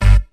neptunesbass2.wav